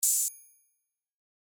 Sharp Open Hat
Sharp-Open-Hat.wav